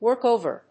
アクセントwórk óver